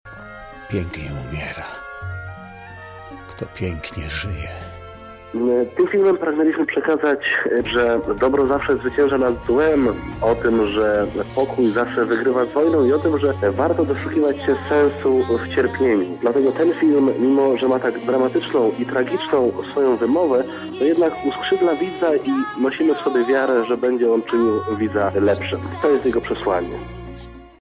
zerwany-kłos-news.mp3